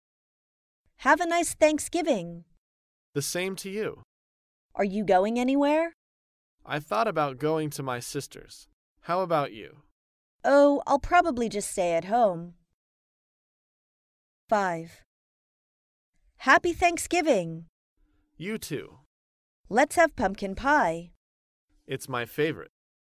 在线英语听力室高频英语口语对话 第330期:感恩节问候的听力文件下载,《高频英语口语对话》栏目包含了日常生活中经常使用的英语情景对话，是学习英语口语，能够帮助英语爱好者在听英语对话的过程中，积累英语口语习语知识，提高英语听说水平，并通过栏目中的中英文字幕和音频MP3文件，提高英语语感。